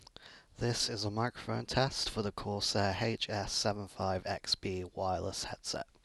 The microphone also does a decent job.
I didn’t have any problems with people being able to hear me clearly, or issues with unwanted noise being picked up loudly.
Corsair-HS75-XB-Mic-Test.mp3